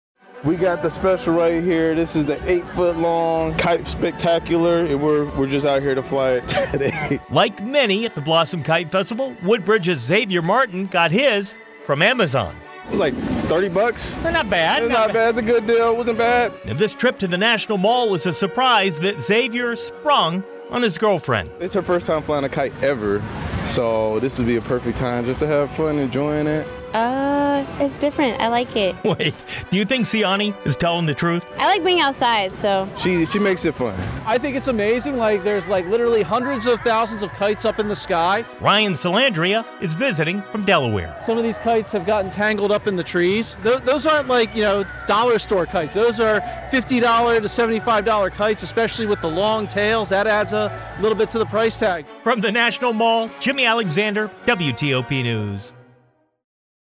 talked to people on the National Mall about how they prepared for the Blossom Kite Festival